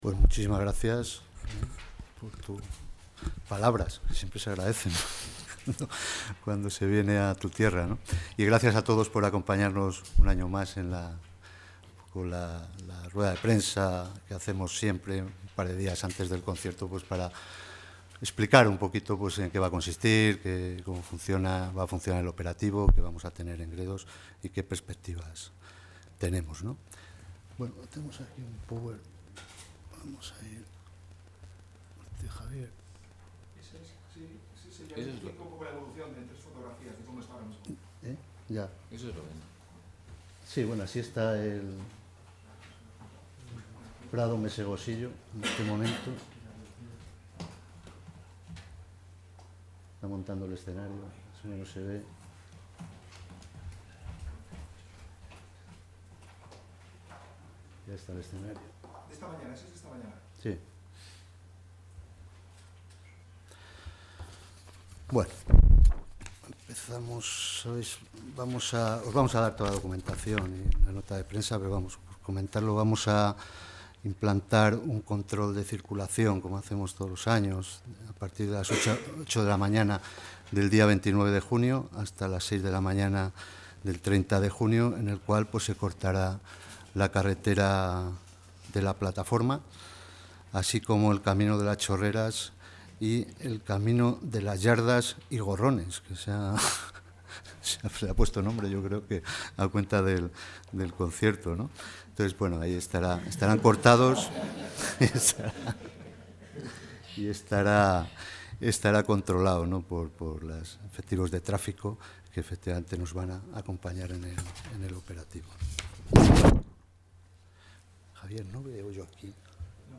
El Director General de Calidad y Sostenibilidad Ambiental, José Manuel Jiménez, ha presentado el operativo previsto para la nueva...
Presentación del operativo.